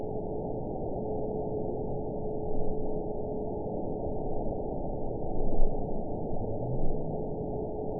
event 921686 date 12/16/24 time 22:17:35 GMT (11 months, 2 weeks ago) score 7.80 location TSS-AB06 detected by nrw target species NRW annotations +NRW Spectrogram: Frequency (kHz) vs. Time (s) audio not available .wav